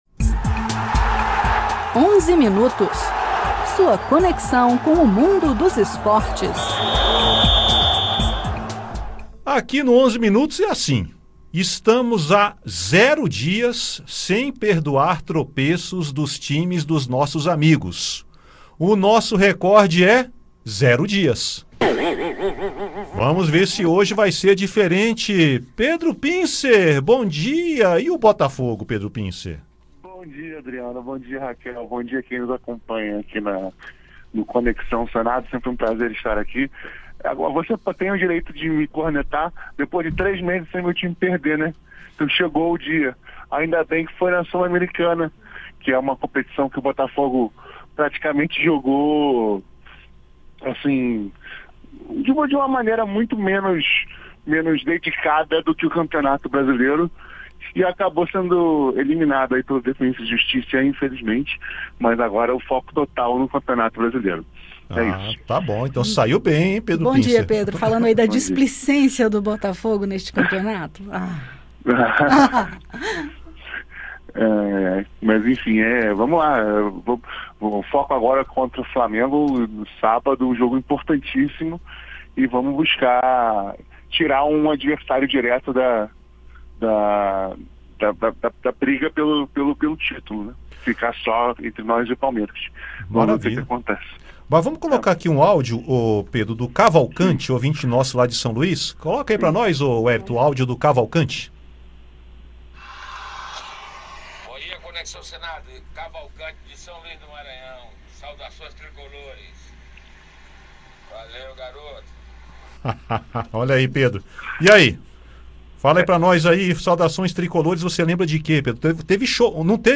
Na edição, confira os comentários sobre a Libertadores e Sul-Americana; as rodadas do final de semana; o Mundial de Basquete; o Sul-Americano de Vôlei e mais.